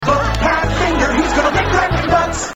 funny skit snl skit